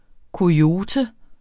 Udtale [ koˈjoːtə ]